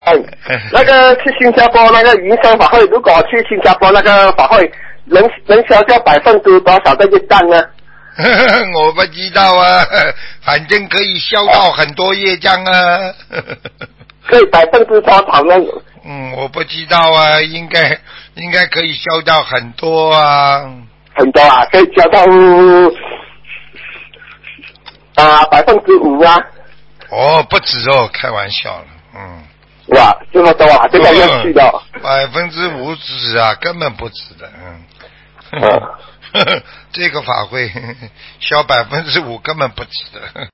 女听众